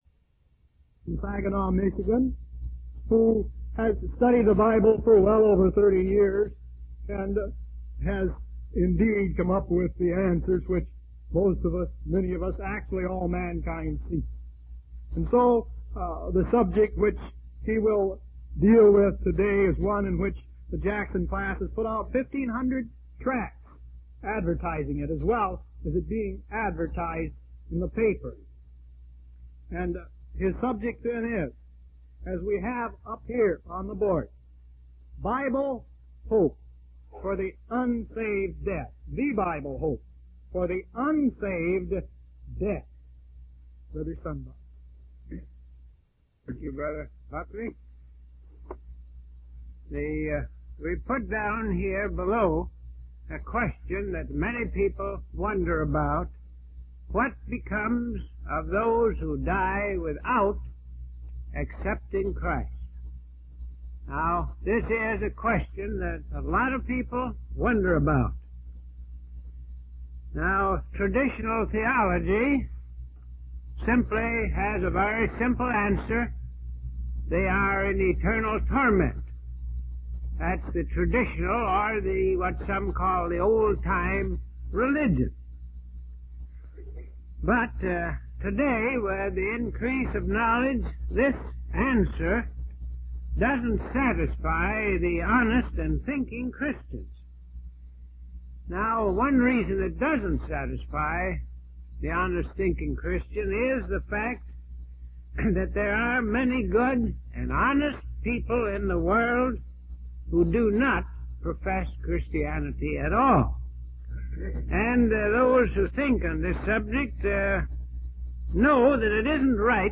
From Type: "Discourse"
Public Discourse